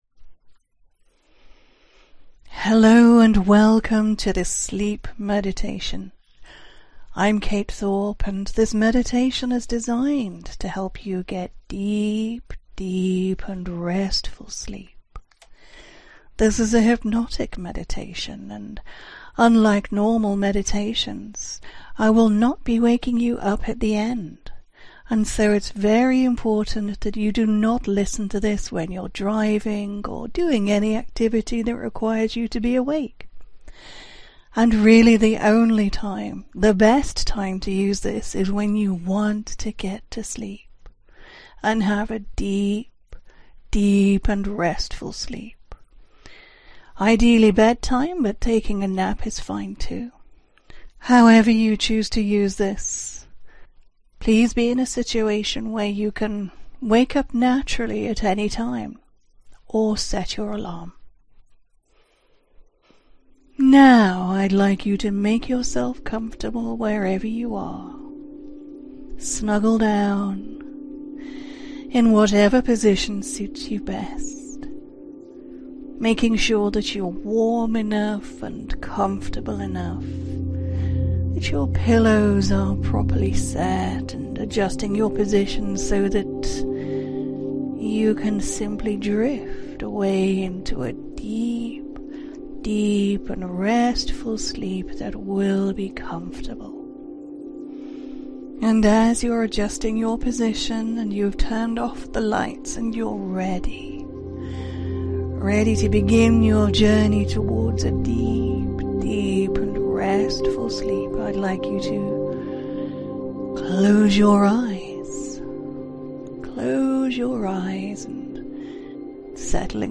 Meditation - deep, deep, restful sleep
Here’s your hypno-meditation, designed to do what the title says… help you drift into a deep, deep, restful sleep.
The hypno-meditation does not wake you up at the end, so please make sure you either have an alarm set or that you can go to sleep for as long as you want.